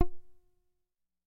标签： MIDI-速度-21 F4 MIDI音符-66 雅马哈-CS-30L 合成器 单票据 多重采样
声道立体声